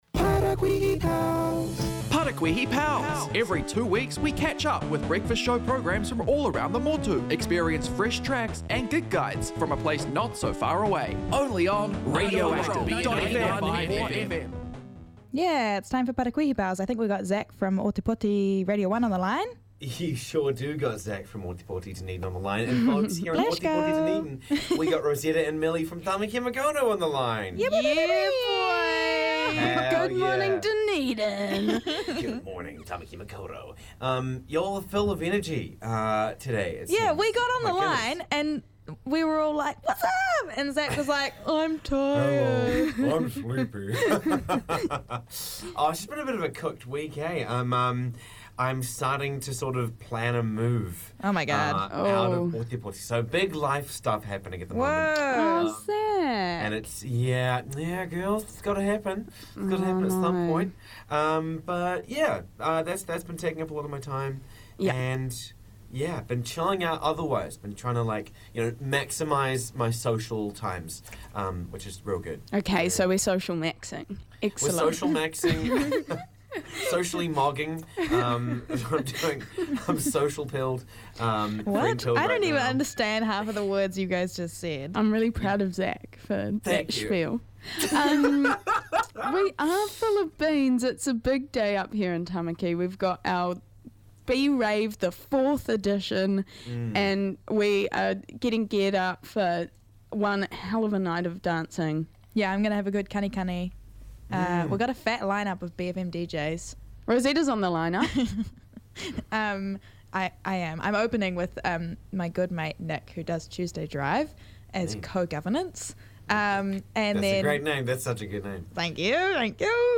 Fortnightly catch-up with fellow Breakfast shows across the Student Radio Network.